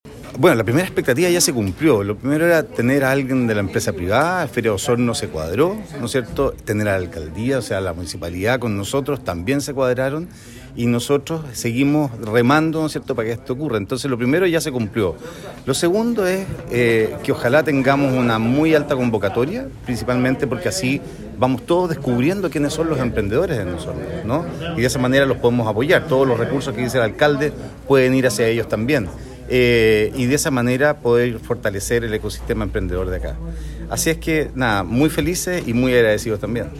En Sala de Sesiones se realizó el lanzamiento de la segunda versión del Concurso de Emprendimiento “Nada Nos Detiene”, que ejecuta la Corporación G-100, con la colaboración del municipio de Osorno y el patrocinio del “Grupo de Empresas Feria Osorno”, y que premiará con $3 millones de pesos al ganador.